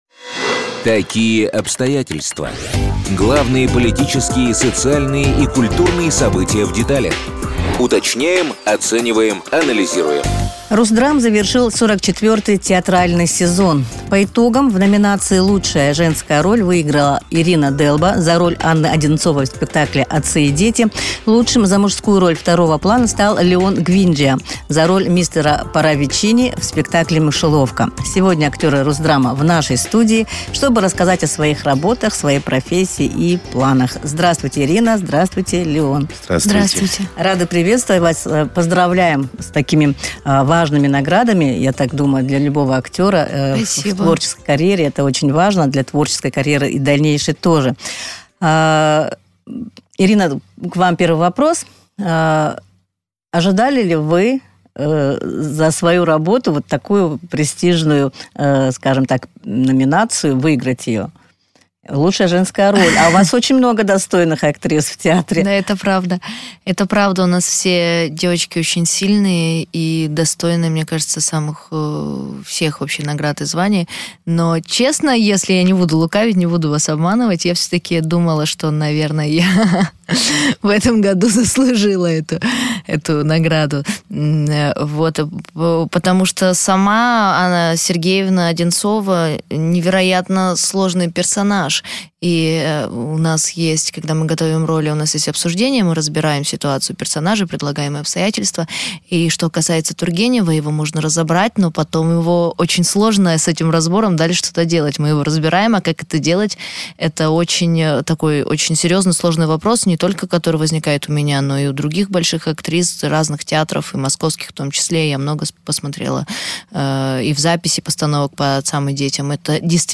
Итоги 44-го сезона РУСДРАМа. Интервью с победителями номинаций